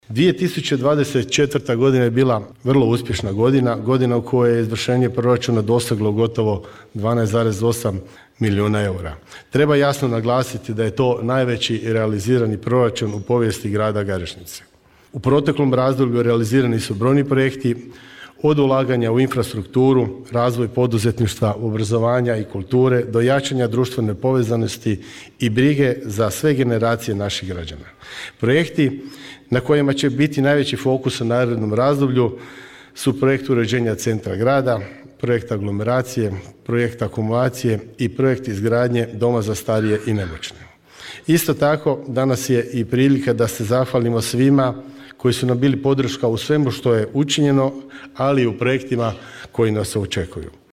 Održana svečana sjednica Gradskog vijeća grada Garešnice uz Dan grada
O postignućima i planovima govorio je gradonačelnik Josip Bilandžija.